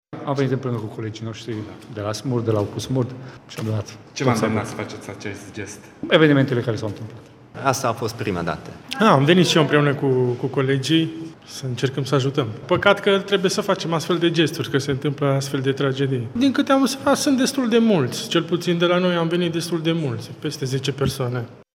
În jur de 10 angajați ai UPU SMURD Tg Mureș s-au prezentat ieri la centrul de donare:
voxuri.mp3